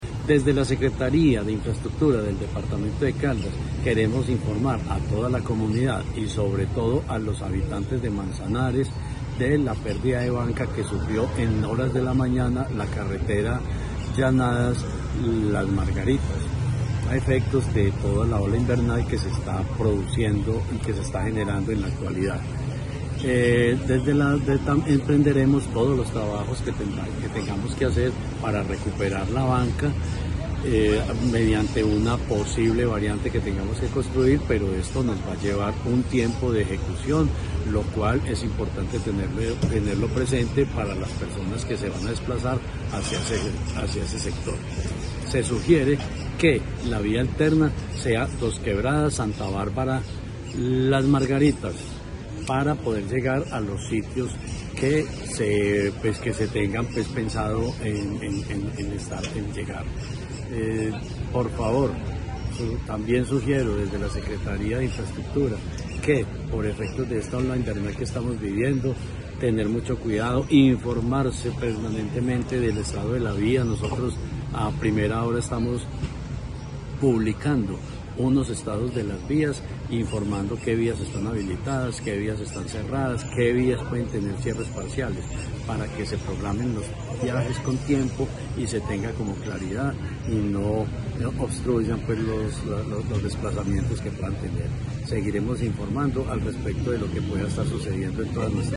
Secretario de Infraestructura, Jorge Ricardo Gutiérrez.